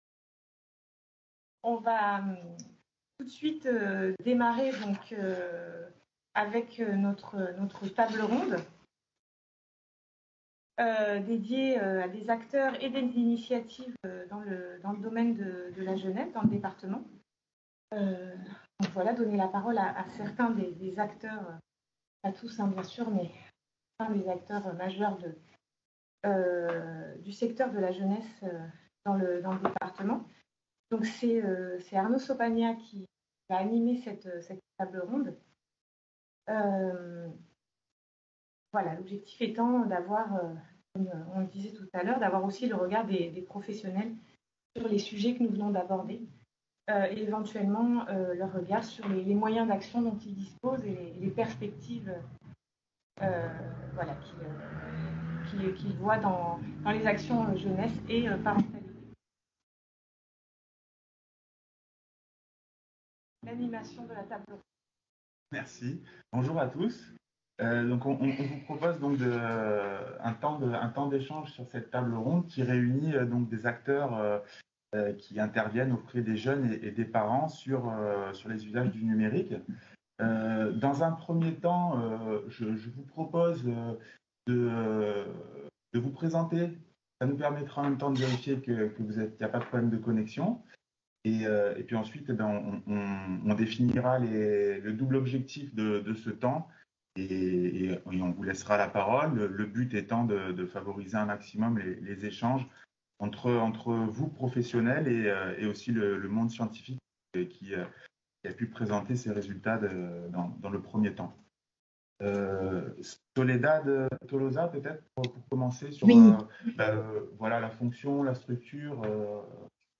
Jeunesse en ligne : des usages aux risques du numérique - Table ronde : acteurs et initiatives dans le domaine des jeunesses en ligne, animée par la Caf des Alpes-Maritimes | Canal U
Enregistrement visioconférence.